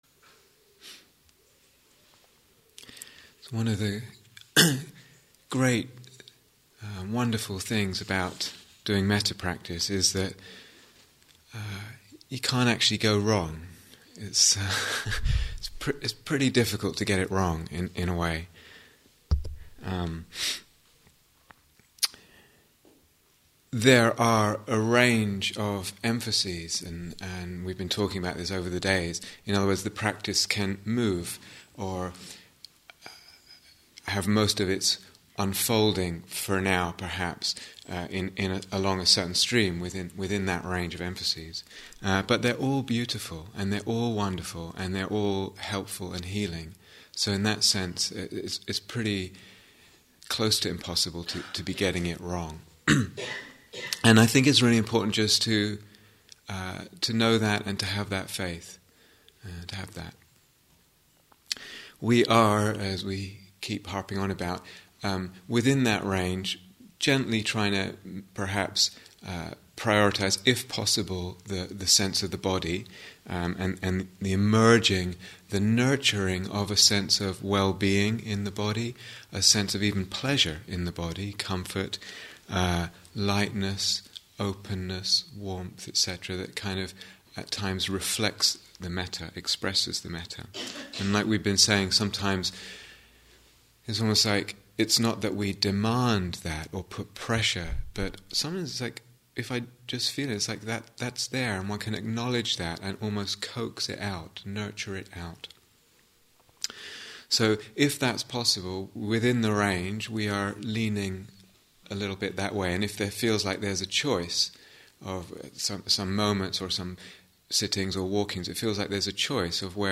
Mettā Instructions and Guided Meditation 5